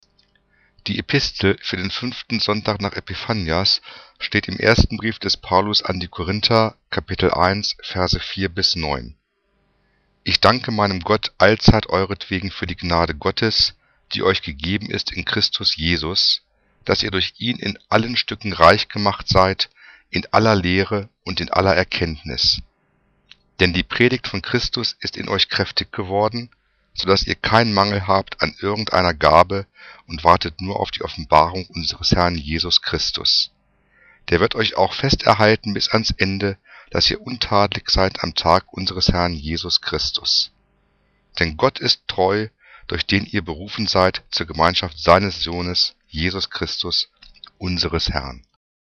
Epistel5nachEpiphanias.mp3